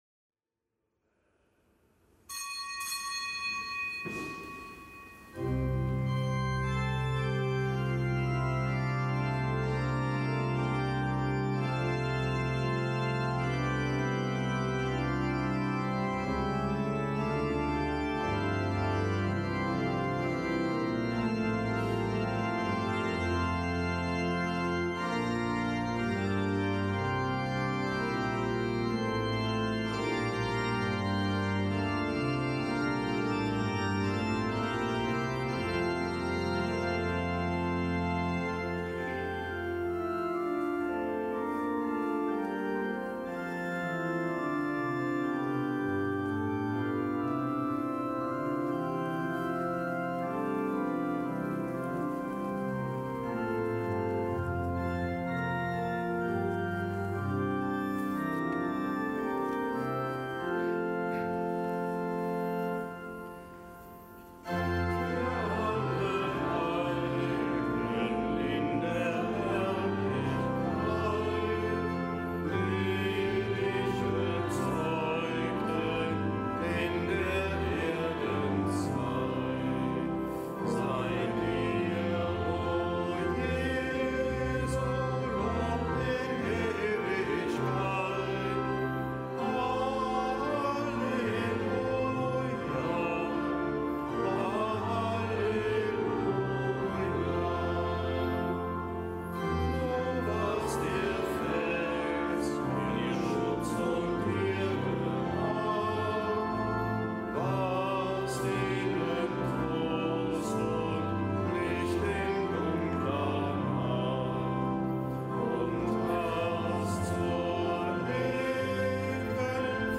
Kapitelsmesse am Gedenktag des Heiligen Johannes Maria Vianney
Kapitelsmesse aus dem Kölner Dom am Gedenktag des Heiligen Johannes Maria Vianney, Pfarrer von Ars.